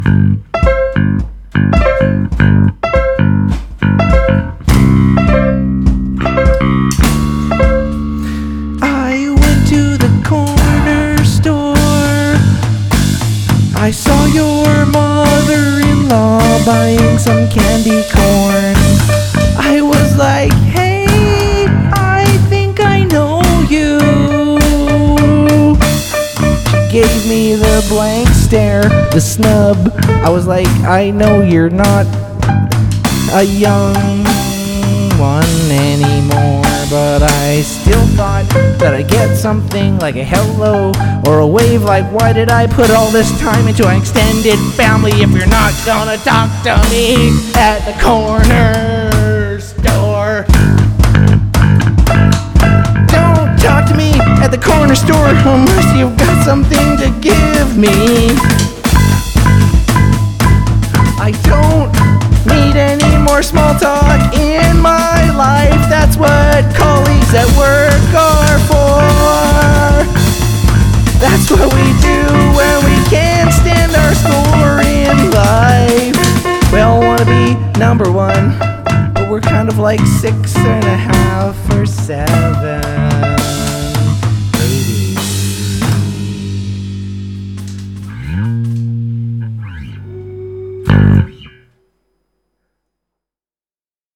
Pop Rock
Bass Guitar
Synthesizer
Main Vocals
Drums